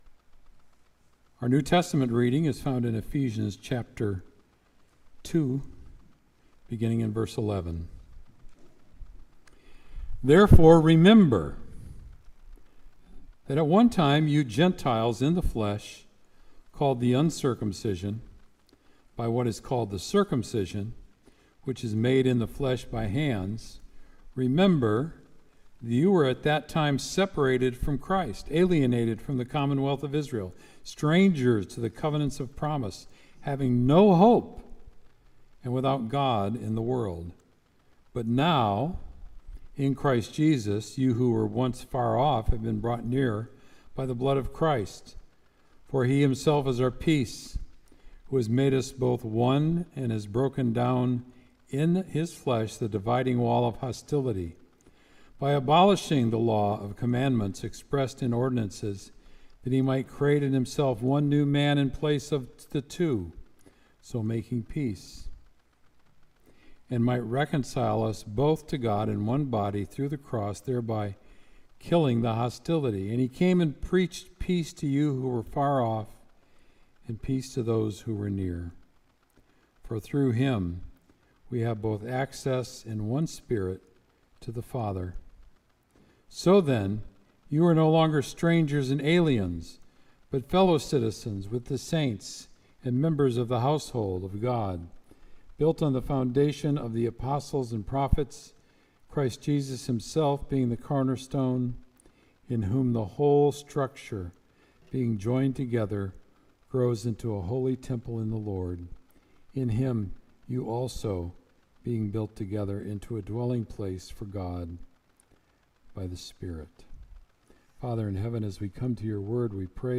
Sermon “Remember”